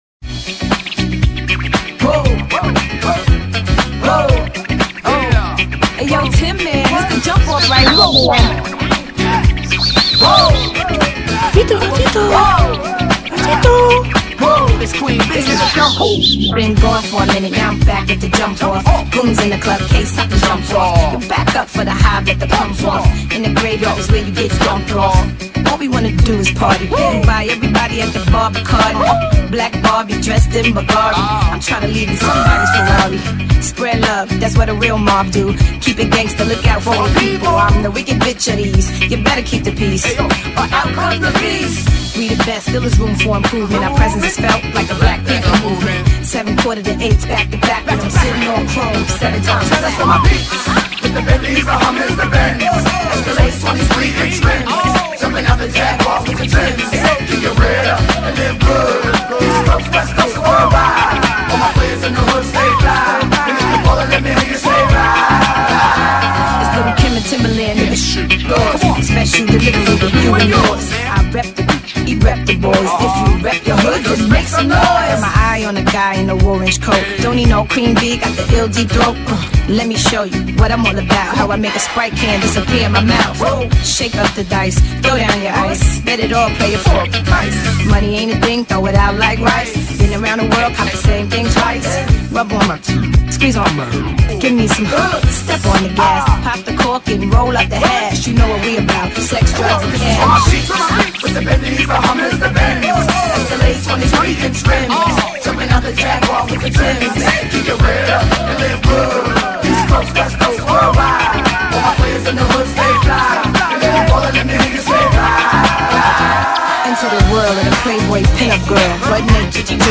Tu página web de mashups y bootlegs en España y Cataluña